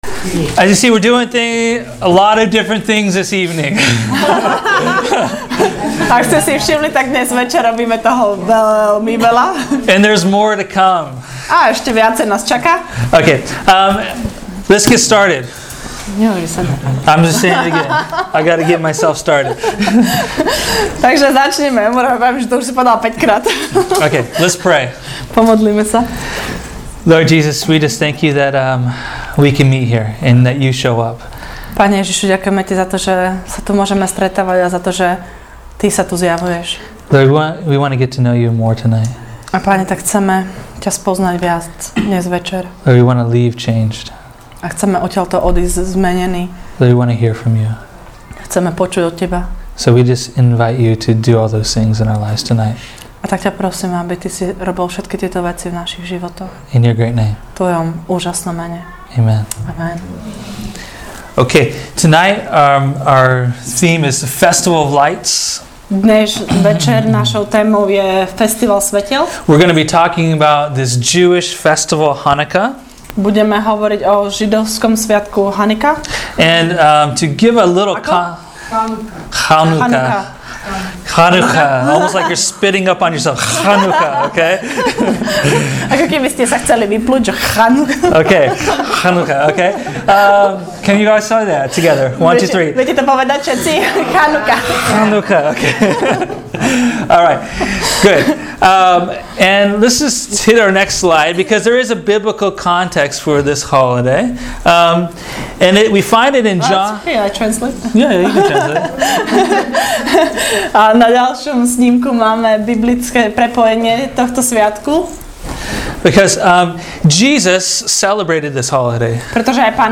Teaching: “Festival of Lights”